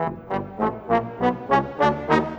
Rock-Pop 20 Trombone _ Tuba 01.wav